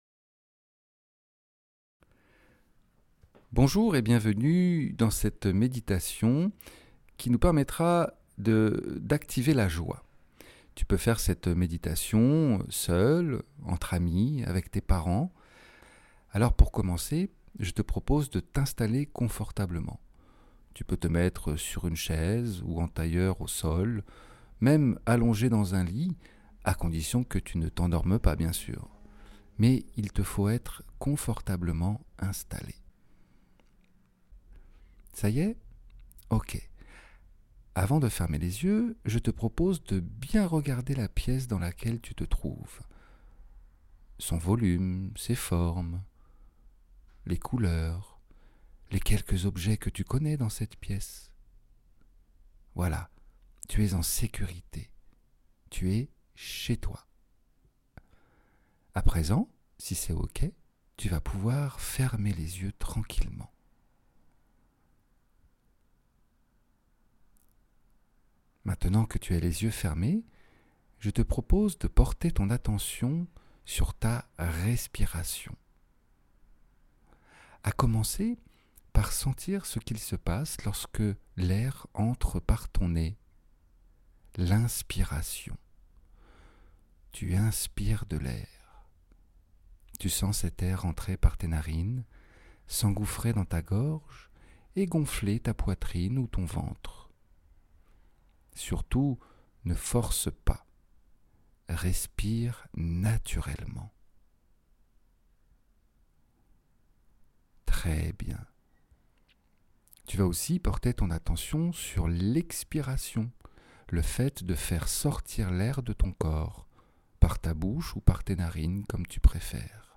Méditation guidée parent-enfant pour cultiver et distribuer la joie
meditation-joie-enfant.mp3